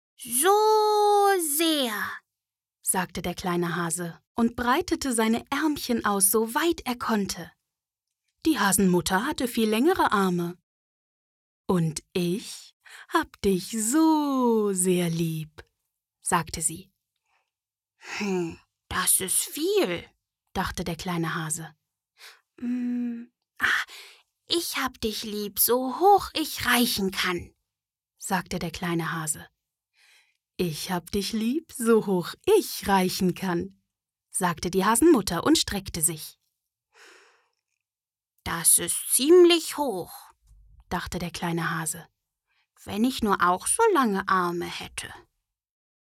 Kinderhörbuch:
Stimmalter: ca. 18-35 Jahre